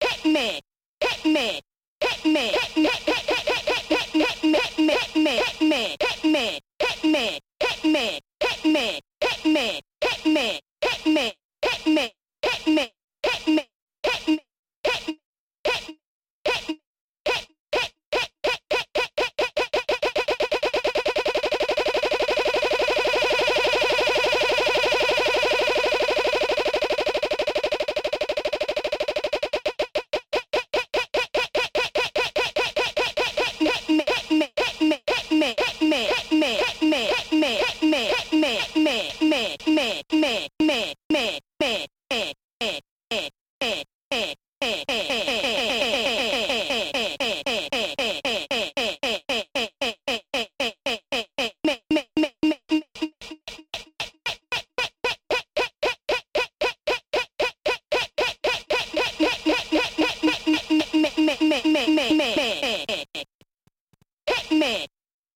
I realised this with an arpeggiator.
arpeggiator rate (how often the sample will be retriggered)
arpeggiator gate (how long the sample will be played until next retrigger)
E-MU E4XT Ultra (CF Cardmod & 80GB HDD, EOS 4.7)
stuttereffect.mp3